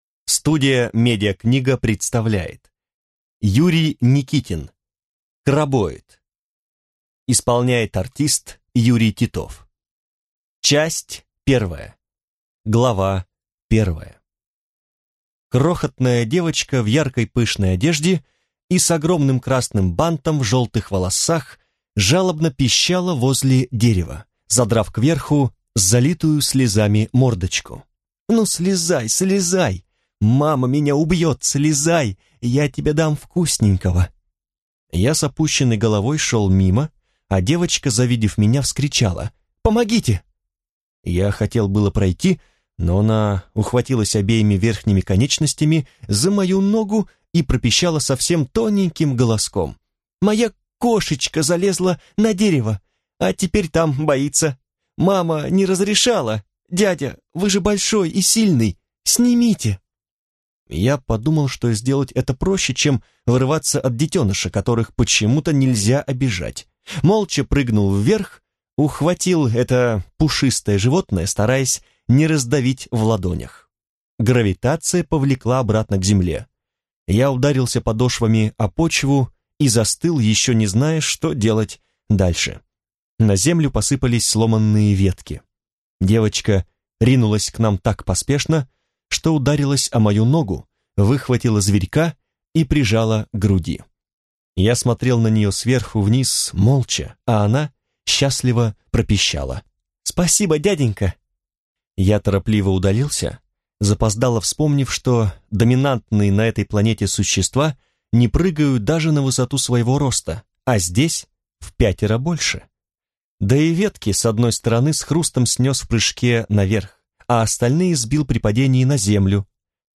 Аудиокнига Крабоид | Библиотека аудиокниг
Прослушать и бесплатно скачать фрагмент аудиокниги